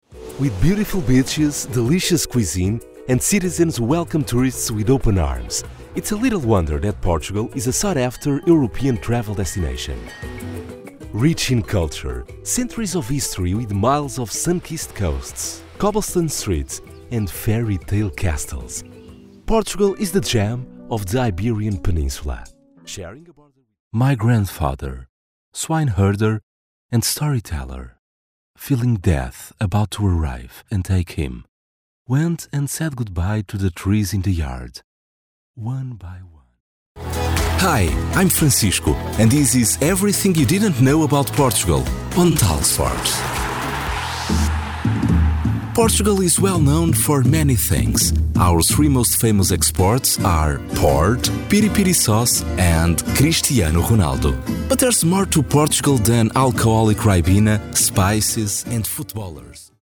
Voice reels
I have a low tone, perfect for elearning, explainers, and commecial ads.
Microphone: Neumann TLM 103
Audio equipment: Vicoustic audio booth, Neve 1073SPX preamp, and Apogee Duet 2 A/D converter.